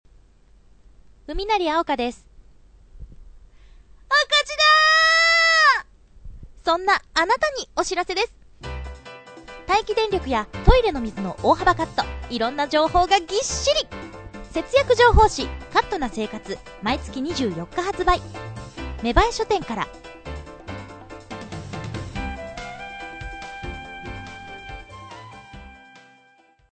架空の雑誌「カットな生活」のＣＭ
しっかり編集されたこのＣＭはあたかもほんとにあるかのようですｗｗ